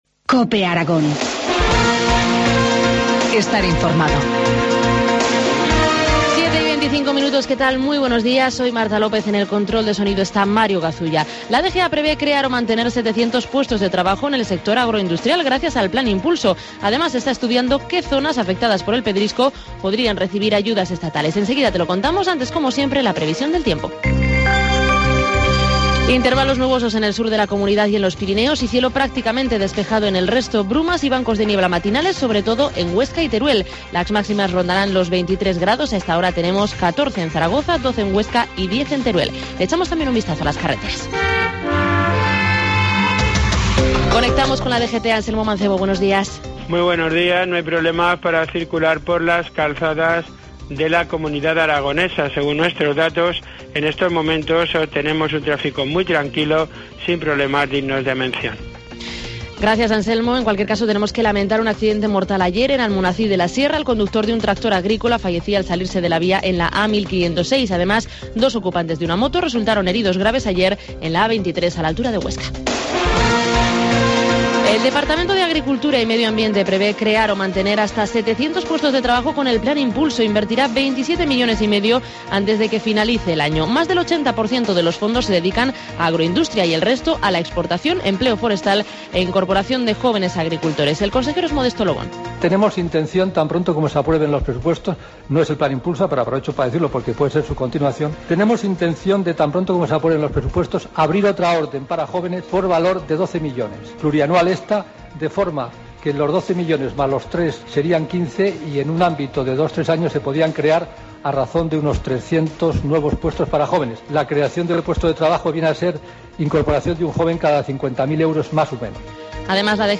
Informativo matinal, miércoles 9 de octubre, 7.25 horas